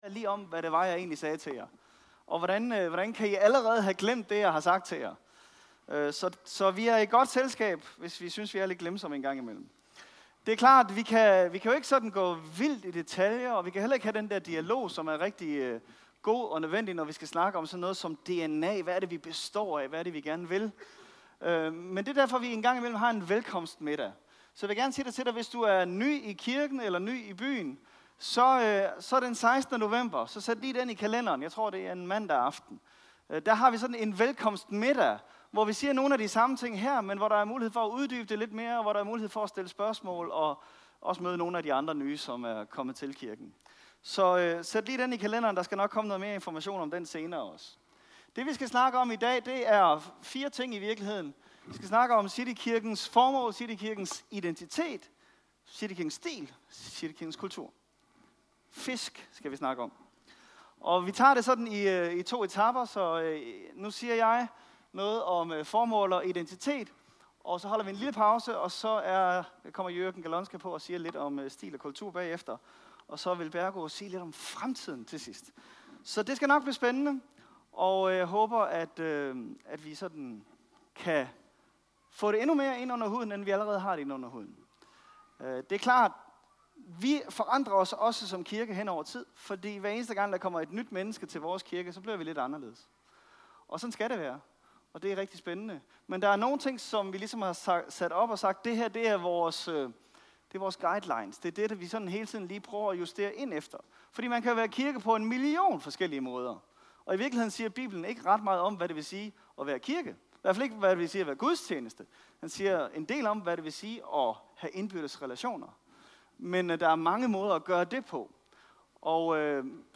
Flere Prædikener fra Præsterne i Citykirken | Download Lydfil